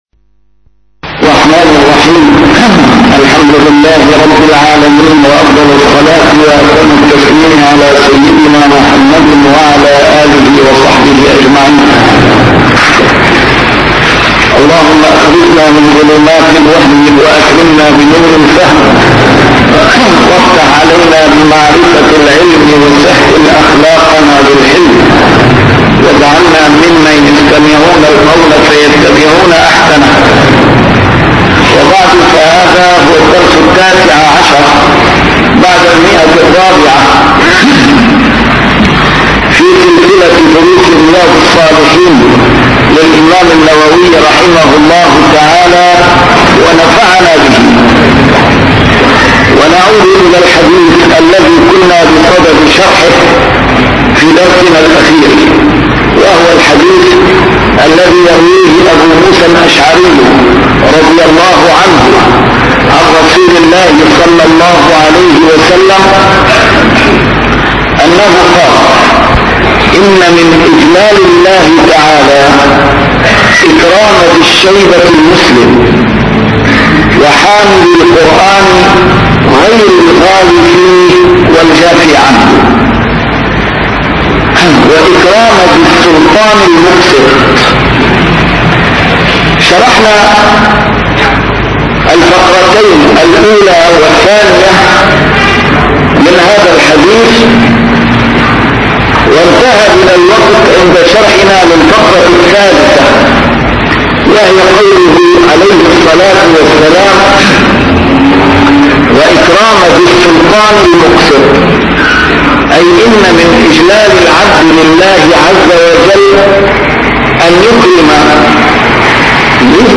A MARTYR SCHOLAR: IMAM MUHAMMAD SAEED RAMADAN AL-BOUTI - الدروس العلمية - شرح كتاب رياض الصالحين - 419- شرح رياض الصالحين: توقير العلماء